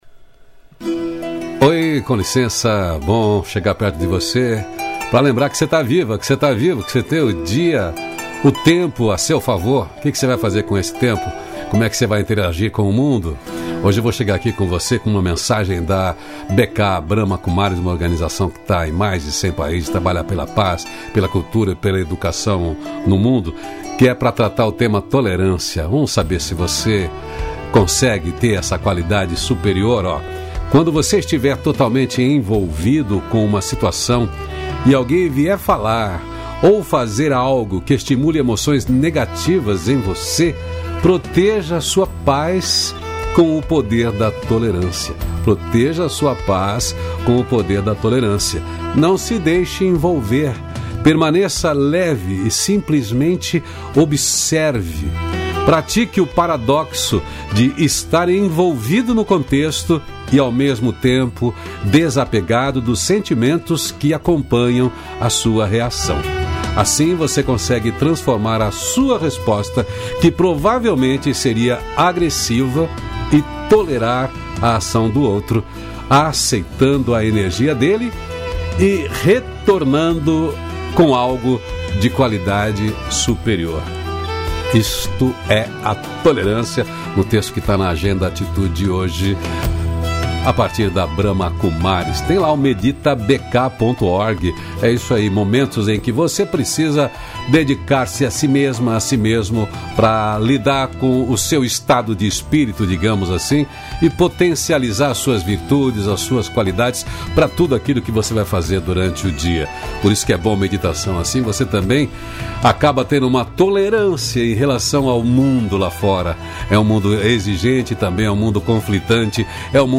CRÉDITOS: Produção e Edição: Onion Mídia Trilha Sonora: “Between the Shadows” Loreena Mckennitt